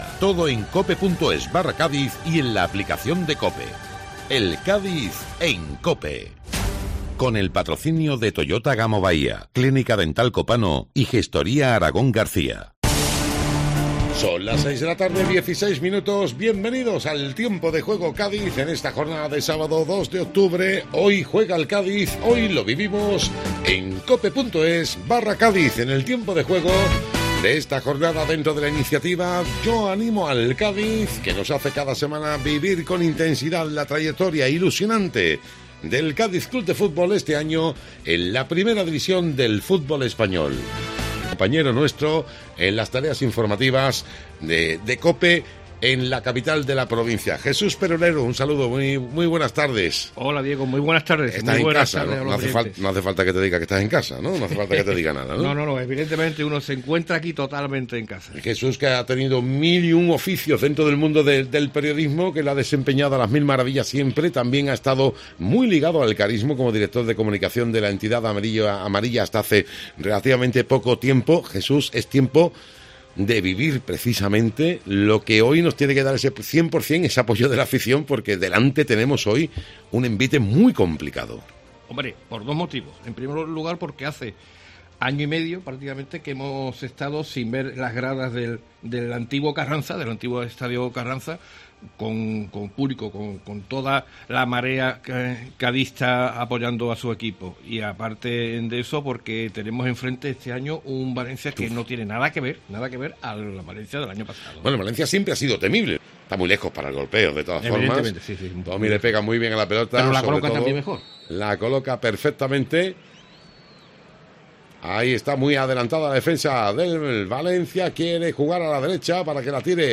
Escucha el resumen con los mejores momentos de los más de dos horas de retransmisión del partido en Tiempo de Juego Cádiz
El resumen sonoro del Cádiz 0-0 Valencia